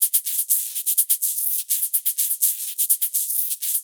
Live Percussion A 08.wav